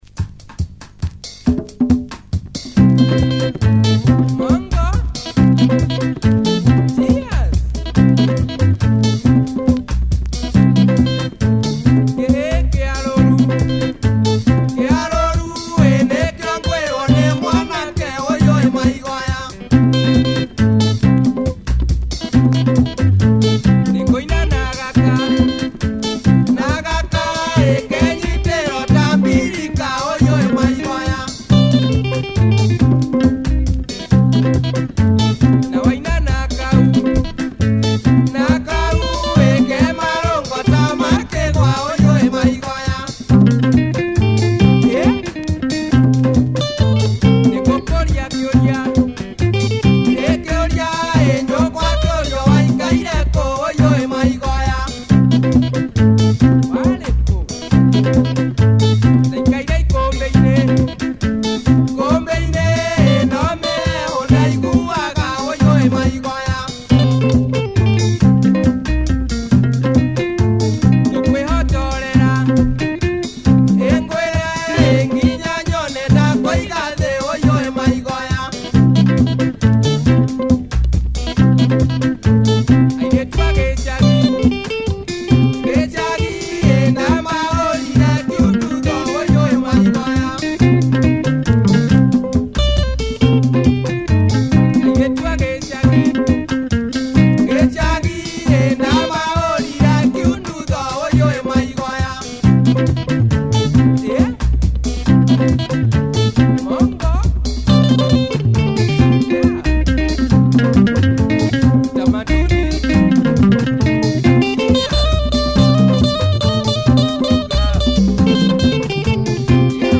Killer bouncy funky Kikuyu Benga shuffler!